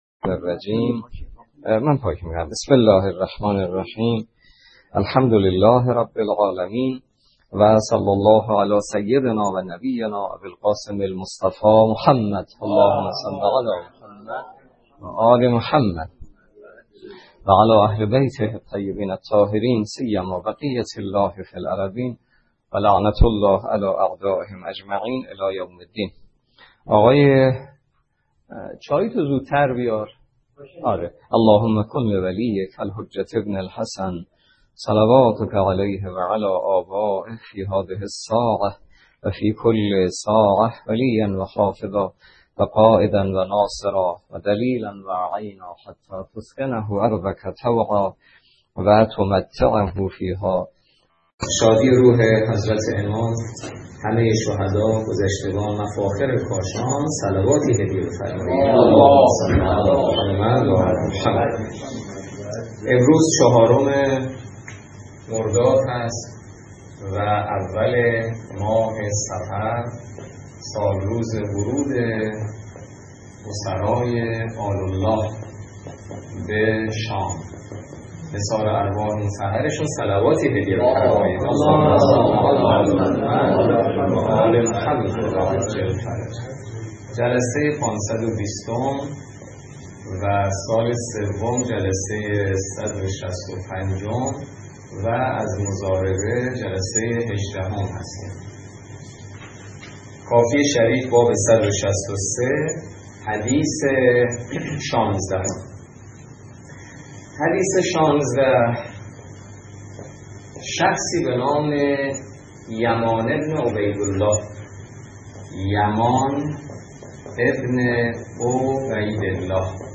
روایات ابتدای درس فقه موضوع: فقه اجاره - جلسه ۱۸